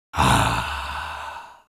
sigh.ogg